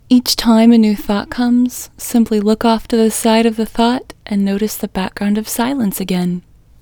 LOCATE IN English Female 18